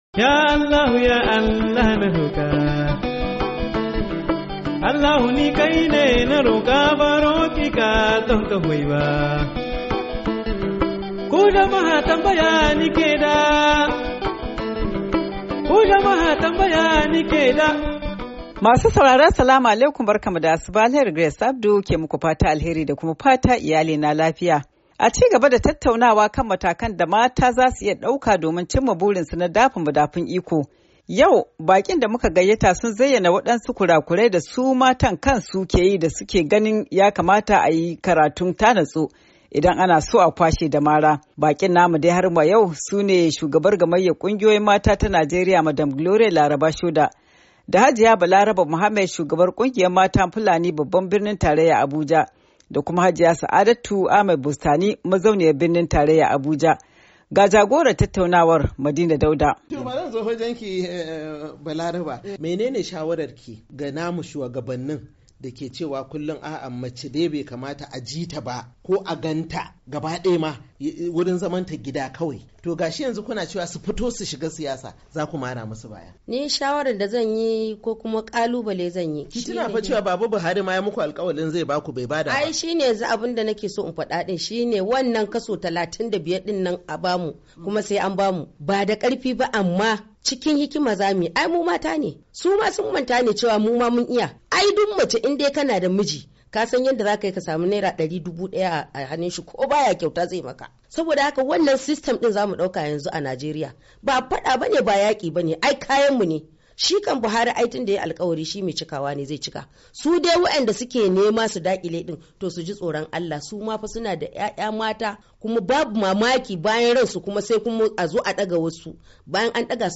DOMIN IYALI:Tattaunawa Kan Zaben Mata A Matsayin Shugabanci- Kashi Na Hudu 10:00"